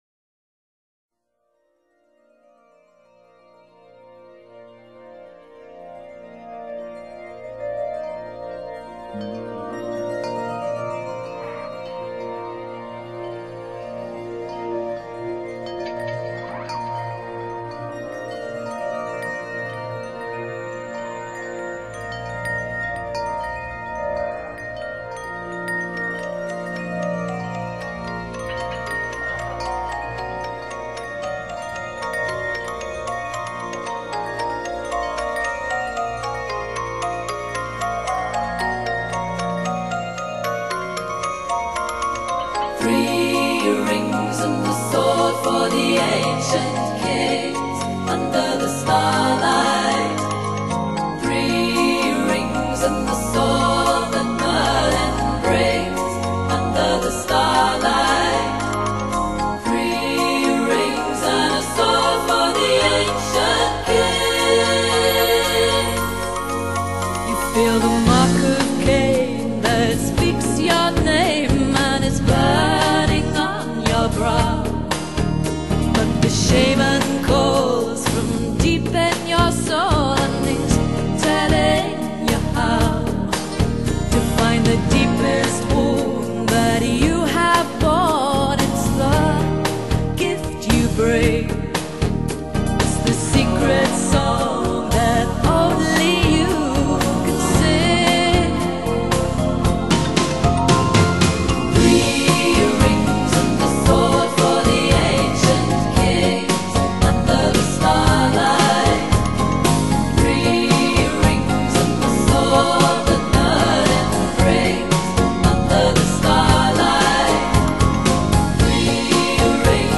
高音假音很美